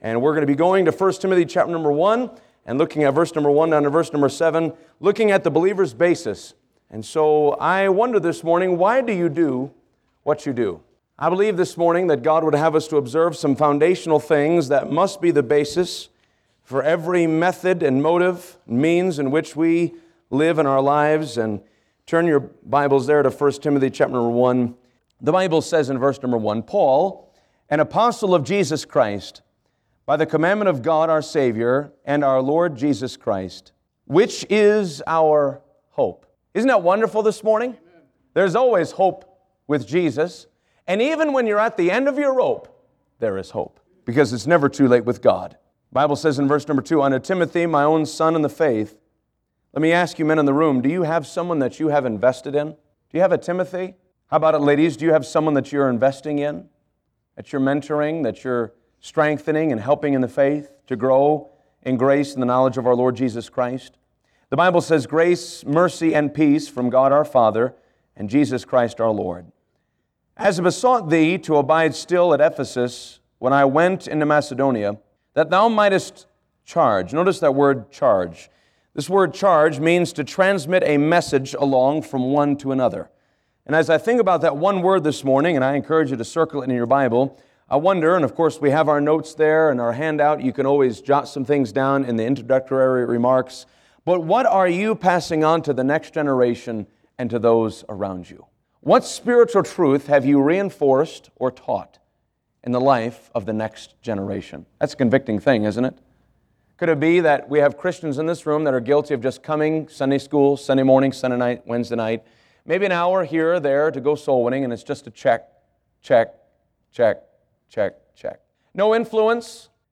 The Believer’s Basis – New Heights Baptist Church